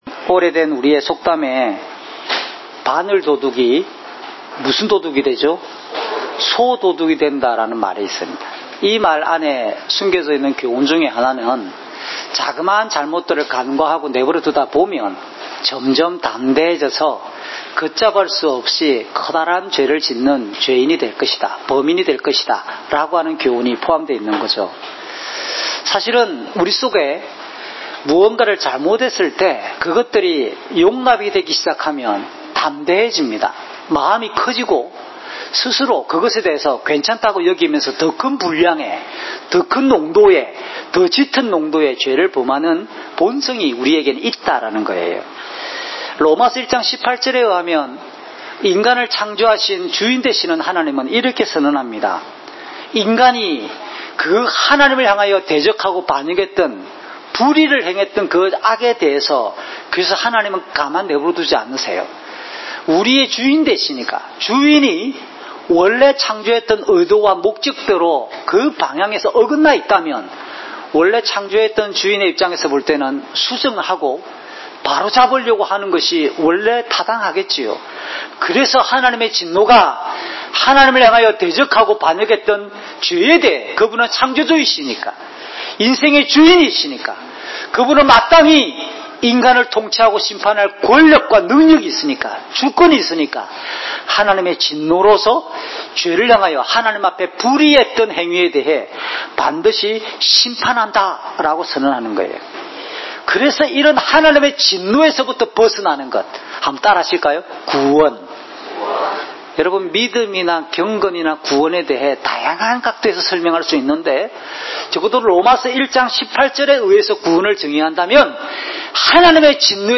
주일 목사님 설교를 올립니다.